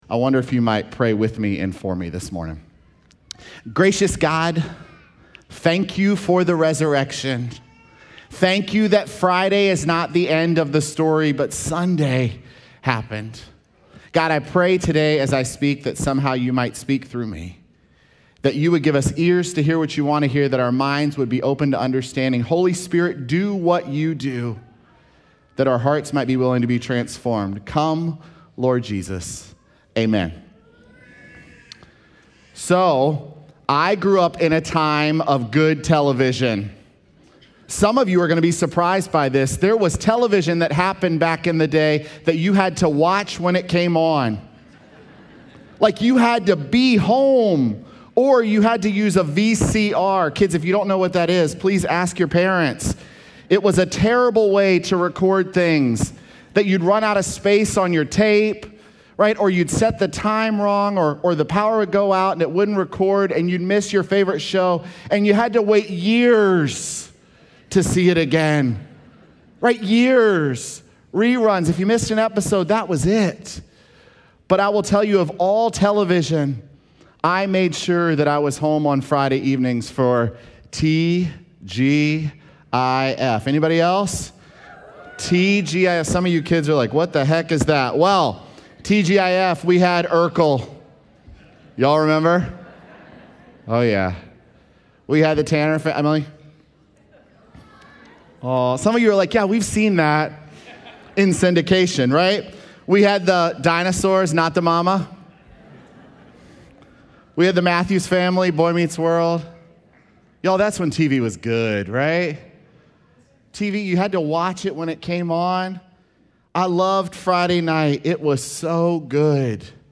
March31SermonPodcast.mp3